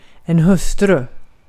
Uttal
Synonymer maka fru äkta maka Uttal Okänd accent: IPA: /hɵ̄sːtrʉ̂ː/ Ordet hittades på dessa språk: svenska Översättning 1. karı {f} Artikel: en .